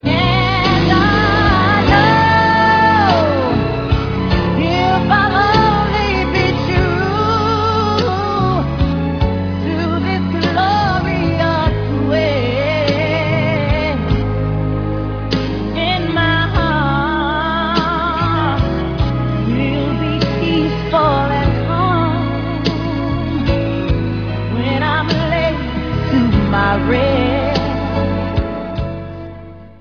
keyboards and drum programming